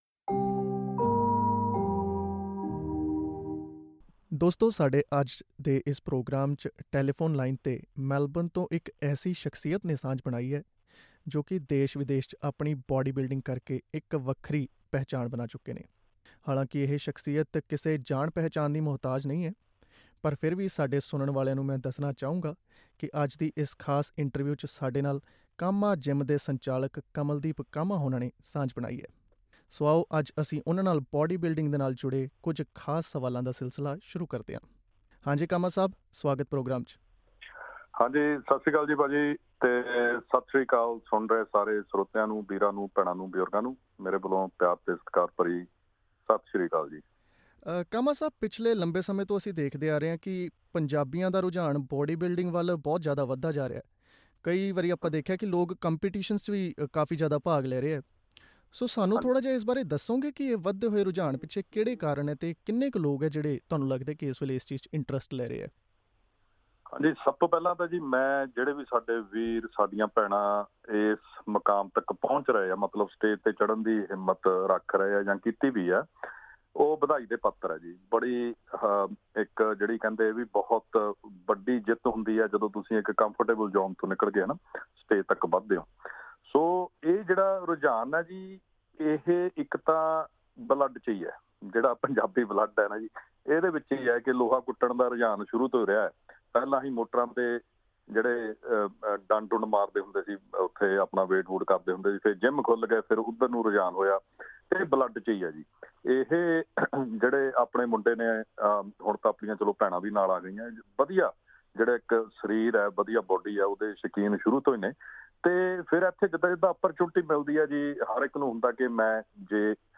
In an interview with SBS Punjabi, the national champion speaks about his journey and the Punjabi community's passion for bodybuilding.